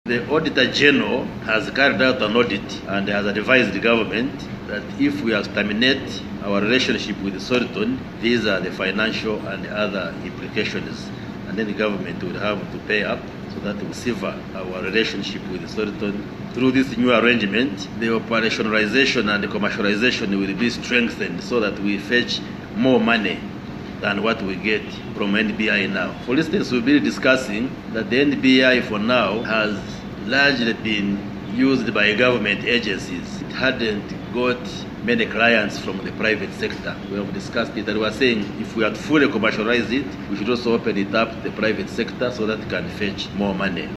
The Minister made the revelation while leading entities under the Ministry to present the Ministerial Policy Statement to the Parliament Committee on ICT and National Guidance on Thursday, 03 April 2025.
AUDIO: Minister Chris Baryomunsi
Hon. Chris Baryomunsi on the need for new UTel deal.mp3